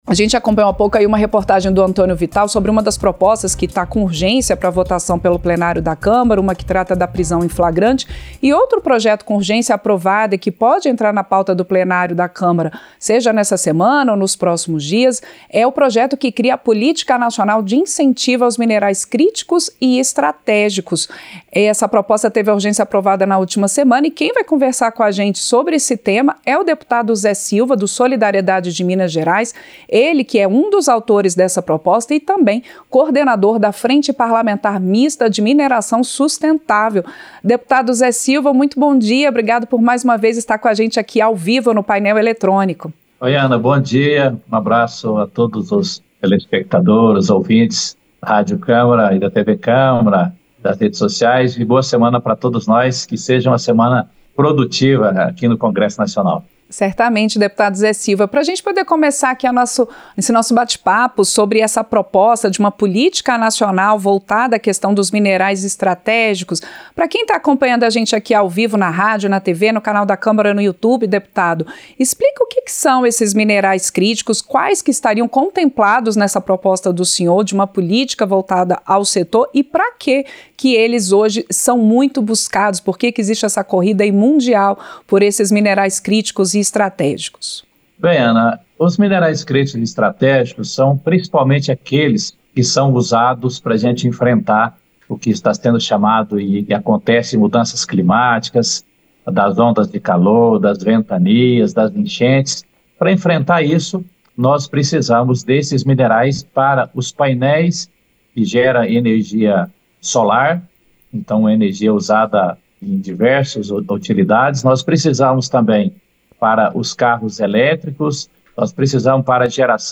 Entrevista - Dep. Zé Silva (Solidariedade-MG)